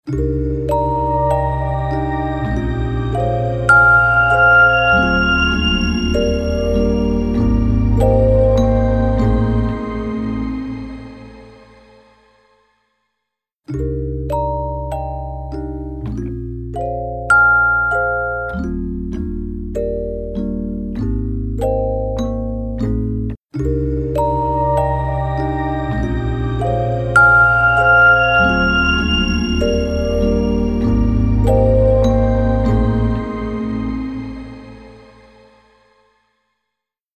ピッチシフティングを融合した、幻想的なリバーブ
ShimmerVerb | Piano | Preset: Celestial Springs
ShimmerVerb-Eventide-Keys-Celestial-Springs.mp3